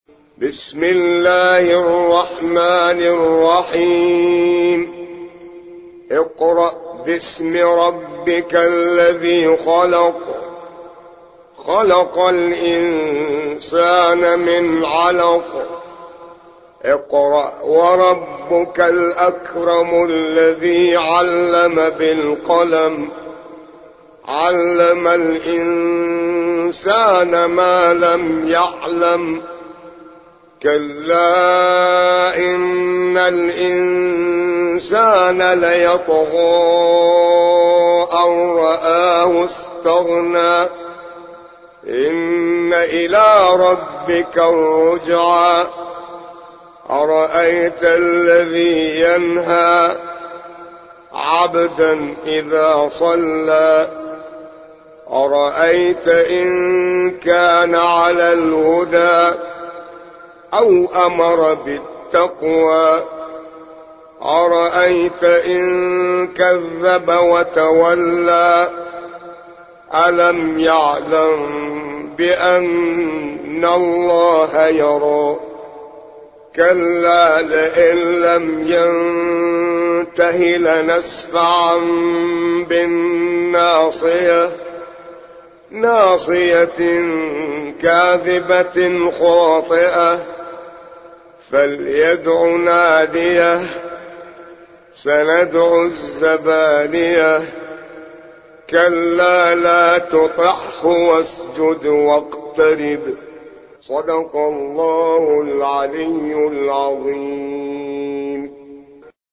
96. سورة العلق / القارئ